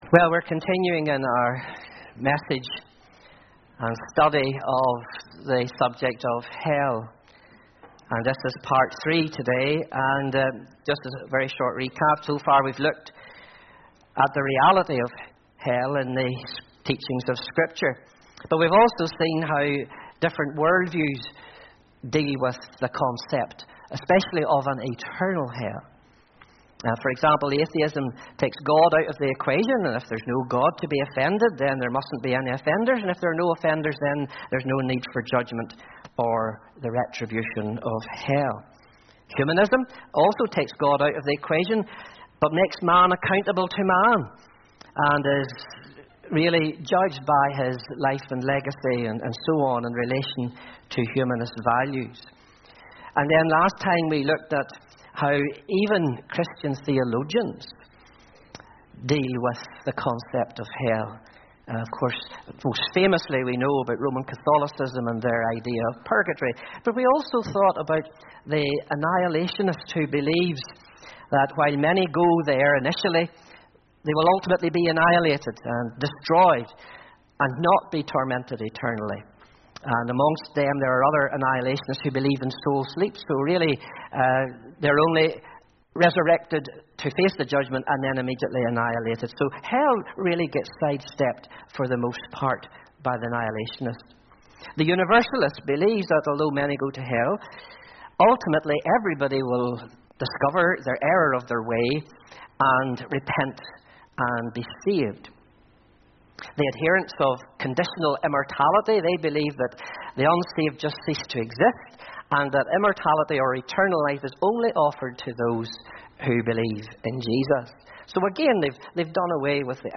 Hell Pt3 – Sunday 28th January 2024 AM – Monkstown Baptist Church